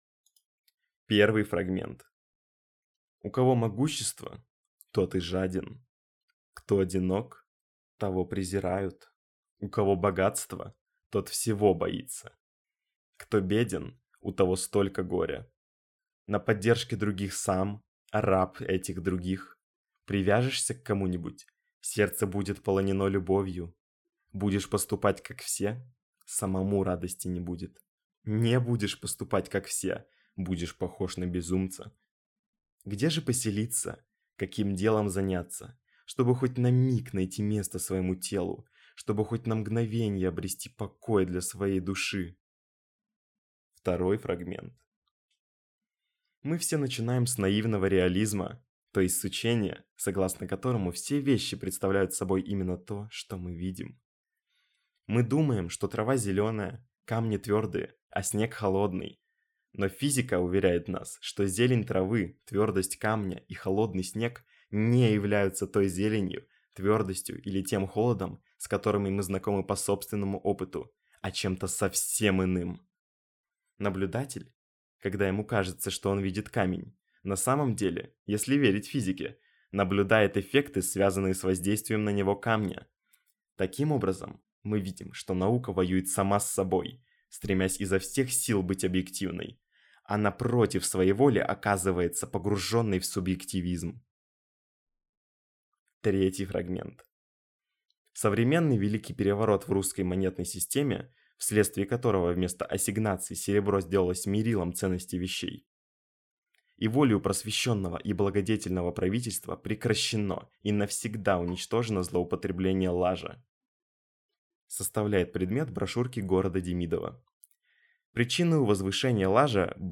Мужской
Также обучаюсь в школе ведущего, поставленная речь и голос, подобный диктору.